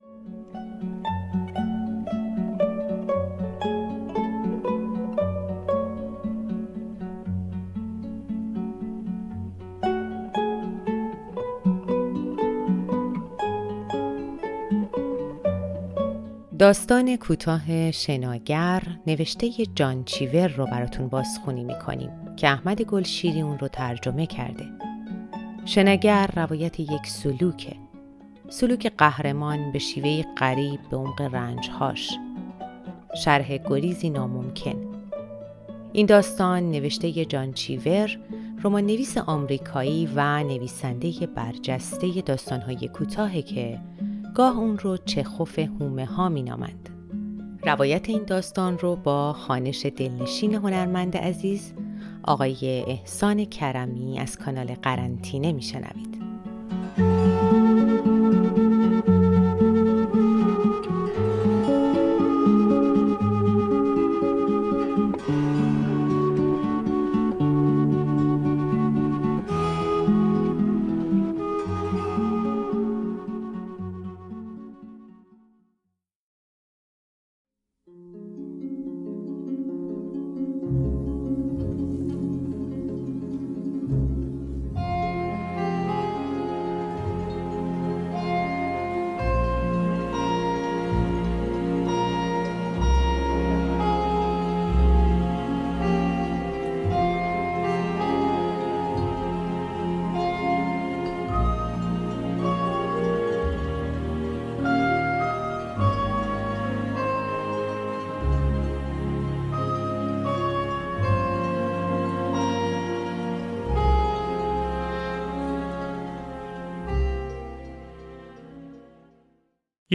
راوی: احسان کرمی (گوینده، مجری تلویزیون، بازیگر و صداپیشه)